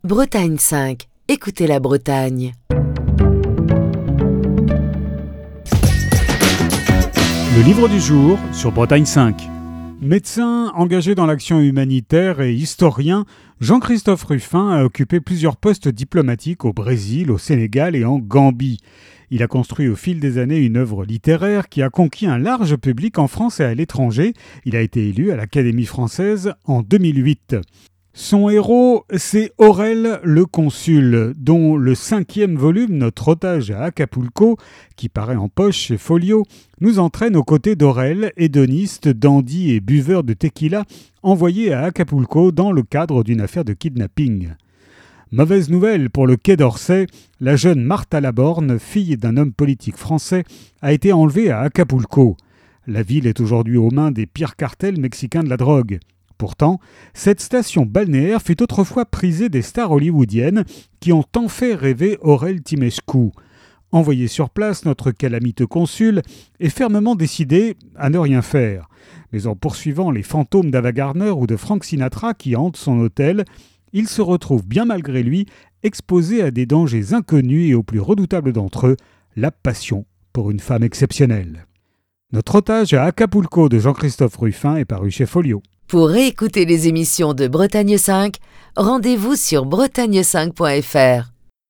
Chronique du 9 juin 2025.